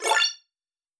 collectItem.wav